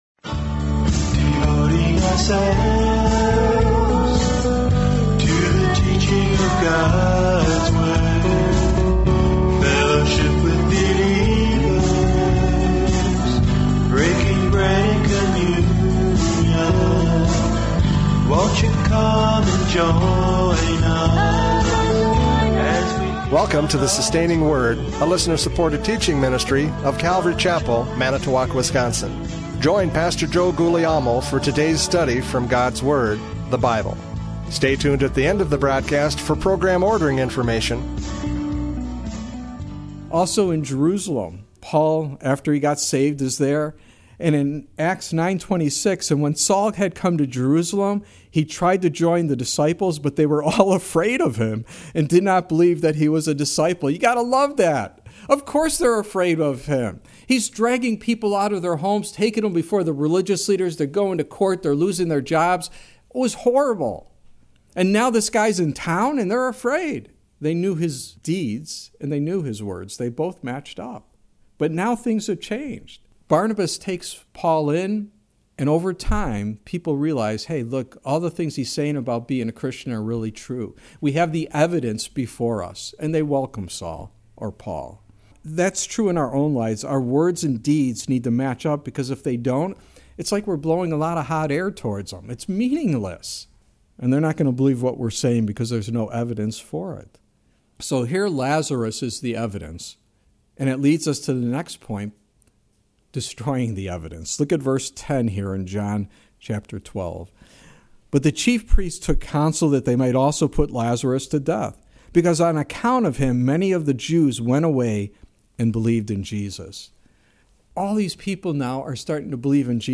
John 12:9-11 Service Type: Radio Programs « John 12:9-11 Destroying the Evidence!